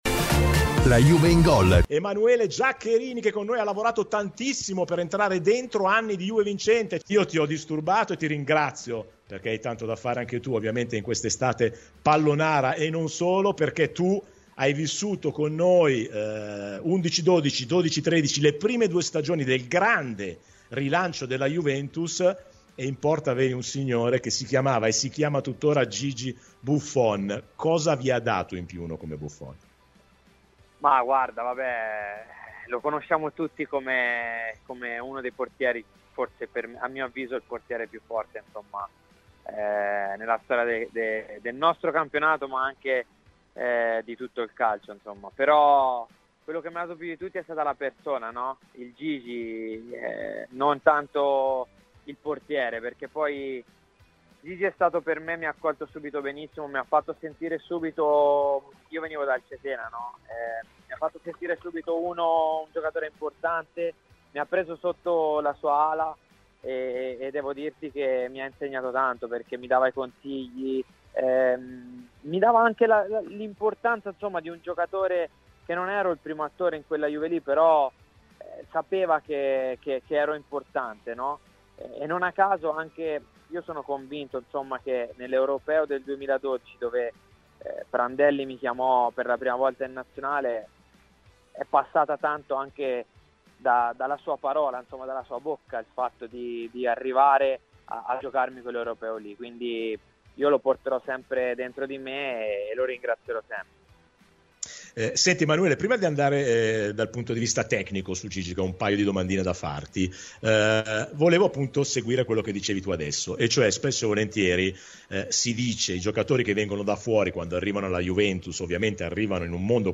Emanuele Giaccherini è stato ospite oggi di “La Juve in Gol” su Radio Bianconera ed è tornato con la memoria a quando condivideva lo spogliatoio con Buffon: “Gigi lo conosciamo di tutti come il portiere, a mio avviso, più forte della storia del calcio, ma io l'ho conosciuto anche come persona e quando arrivai alla Juve mi accolse benissimo, mi ha fatto sentire subito un giocatore importante e mi ha anche insegnato tanto.